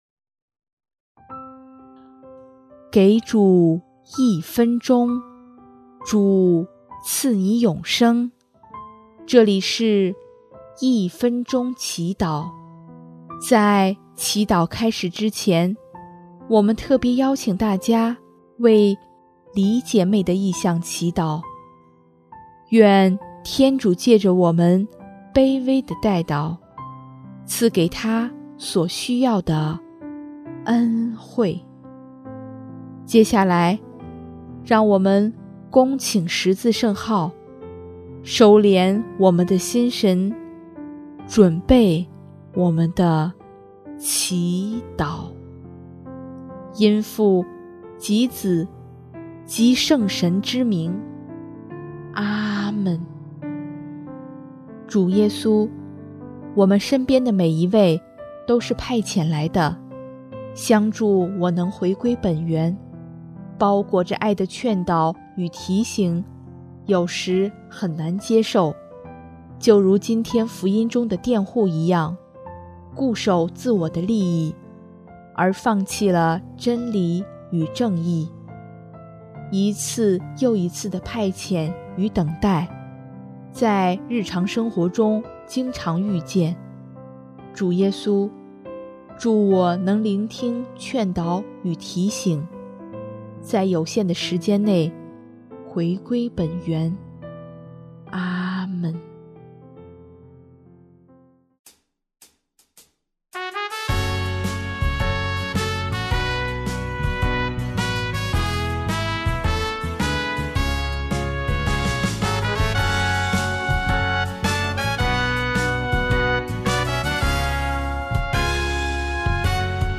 【一分钟祈祷】|6月3日 劝导与提醒